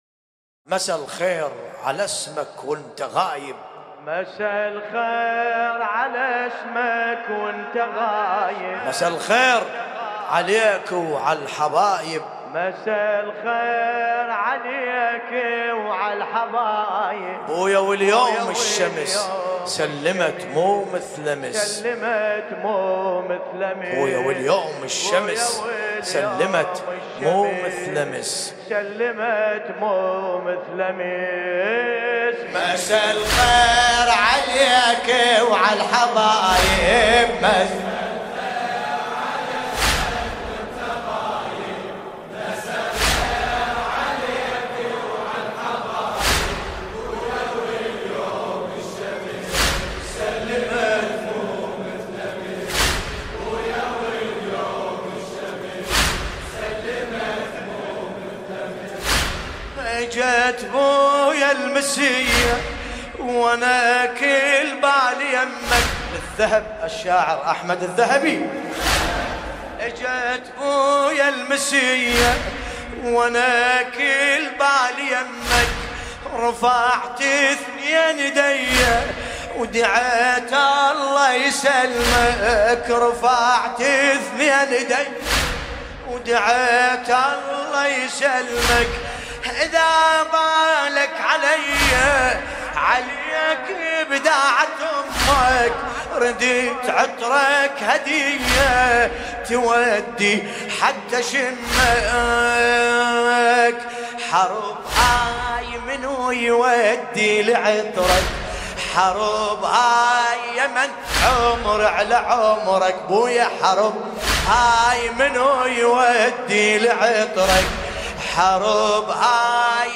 ملف صوتی مسا الخير بصوت باسم الكربلائي